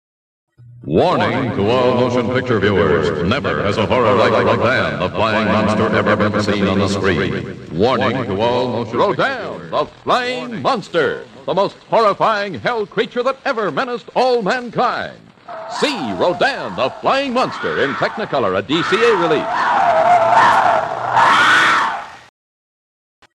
The Flying Monster Radio Spots
20, 30, and 60 seconds radio spots for Rodan!